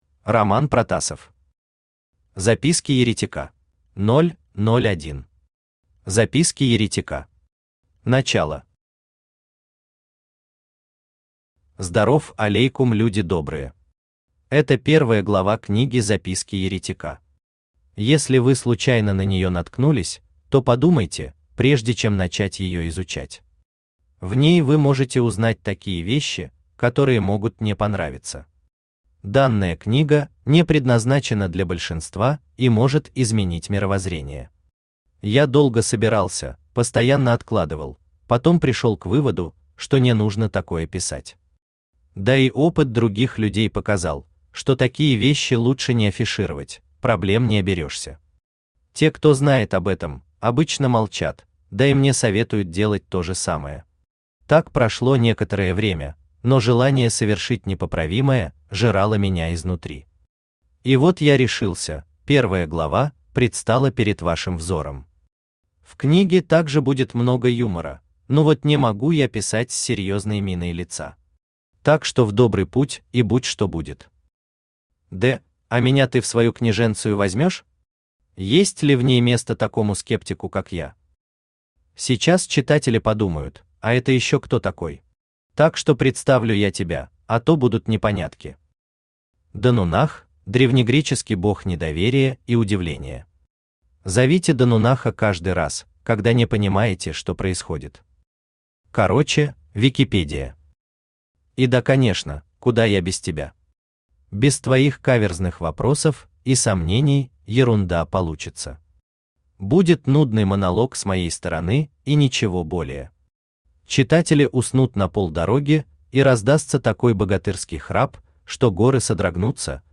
Аудиокнига Записки Еретика | Библиотека аудиокниг
Aудиокнига Записки Еретика Автор Роман Протасов Читает аудиокнигу Авточтец ЛитРес.